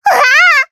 Taily-Vox_Damage_jp_02.wav